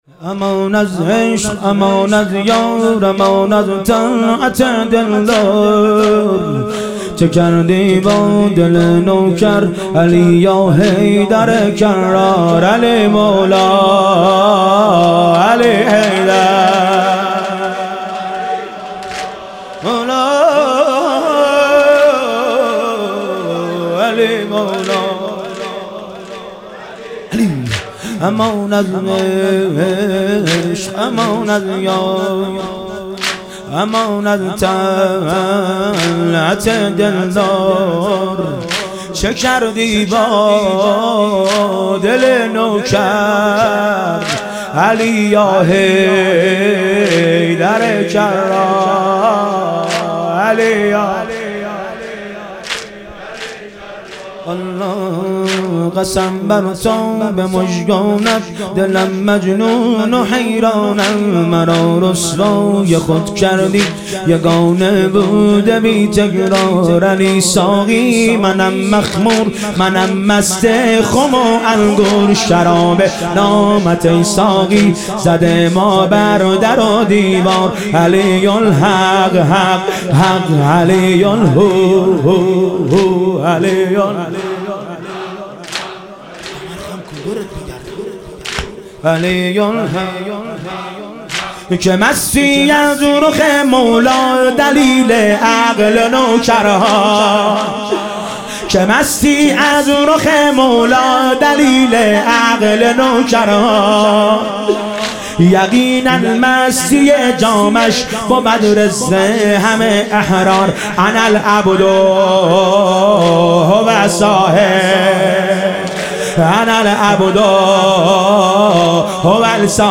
حرکت کاروان سیدالشهدا علیه السلام - واحد